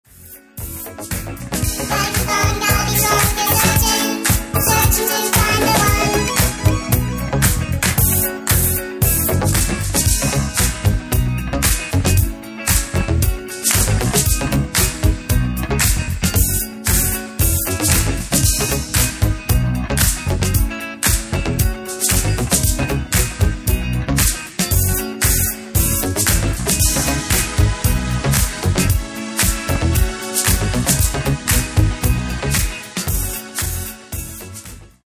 Genere:   Disco | Funky
12''Mix Extended